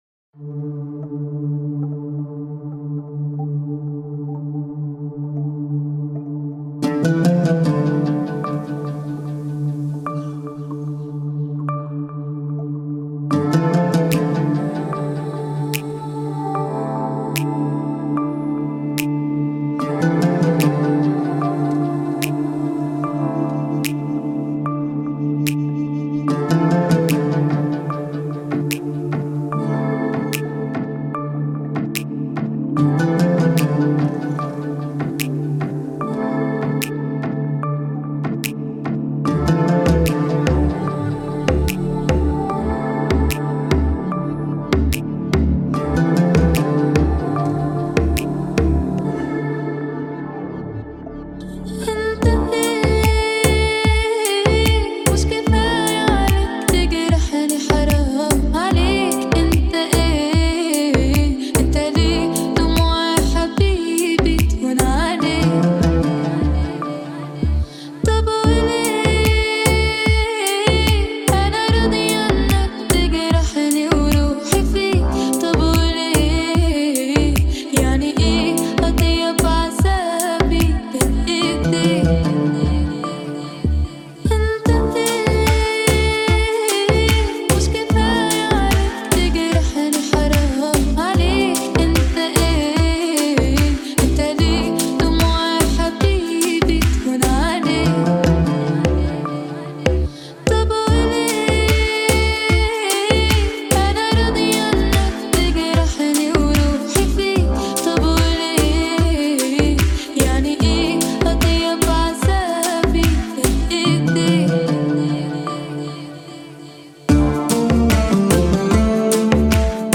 Звучание отличается яркими инструментами и мощным вокалом